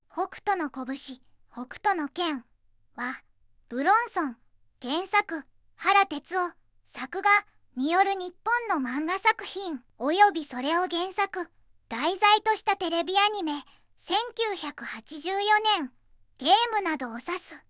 VOICEVOX: ずんだもんを利用しています